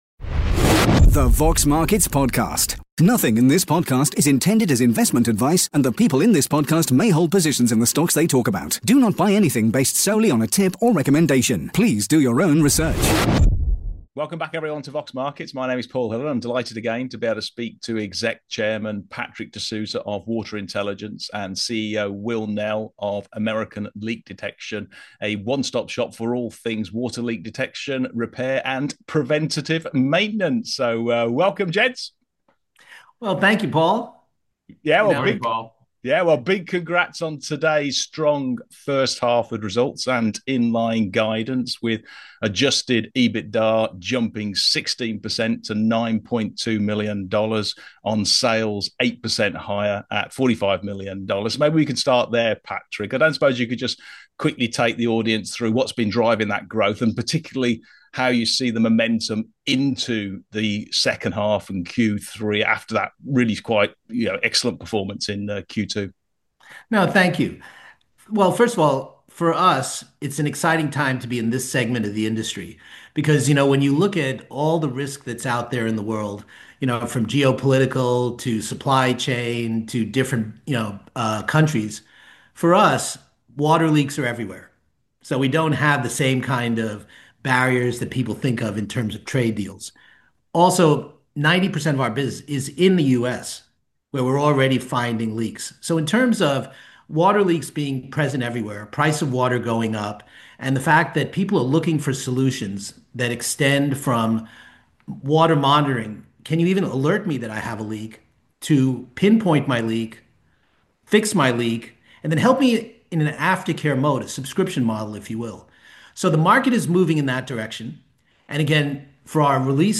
Interview with Exec Chairman